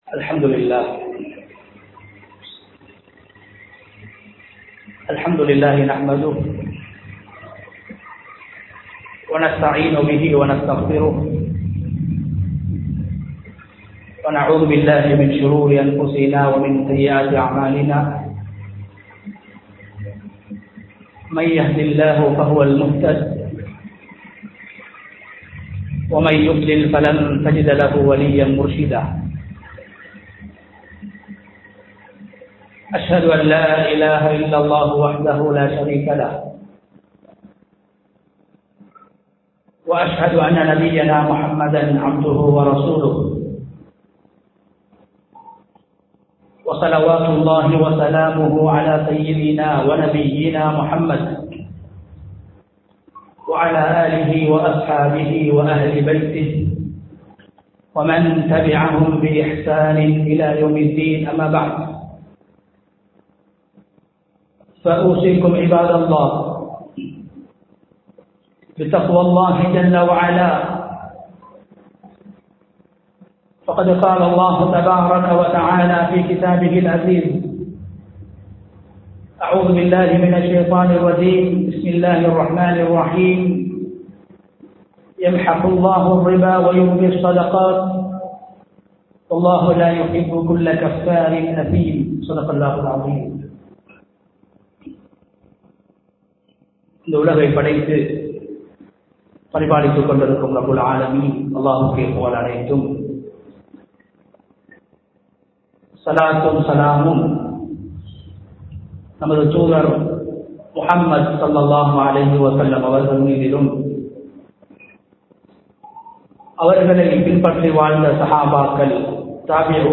ரமழானும் ஸதகாவும் | Audio Bayans | All Ceylon Muslim Youth Community | Addalaichenai